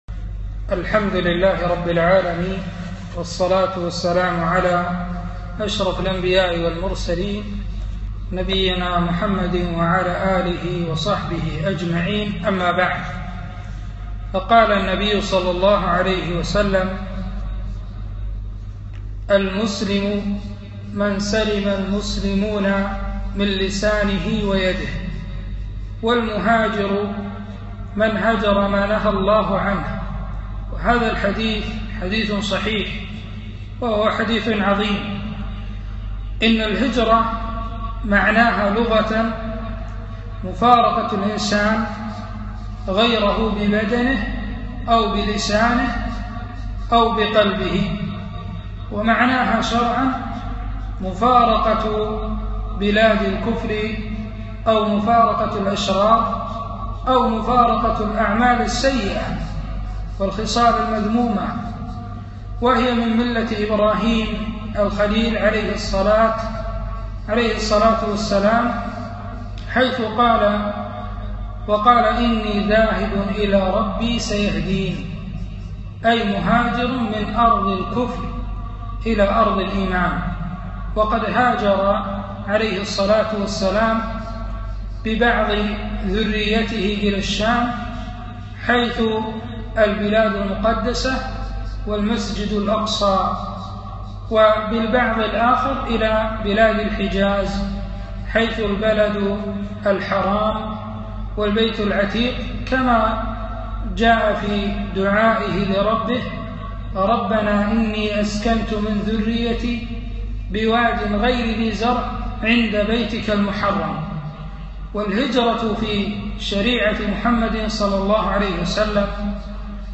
محاضرة - المهاجر من هجر ما نهى الله عنه